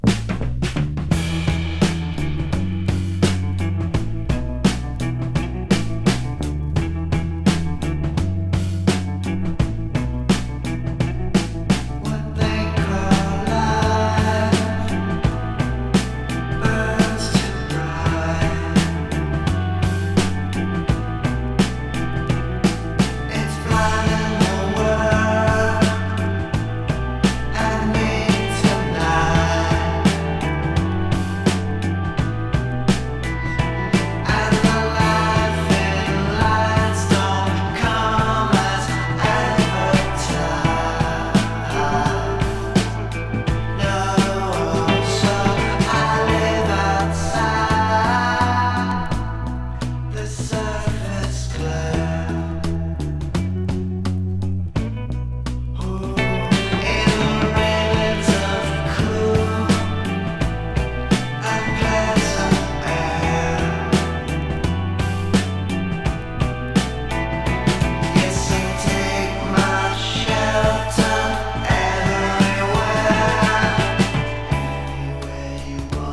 気怠い雰囲気がたまらない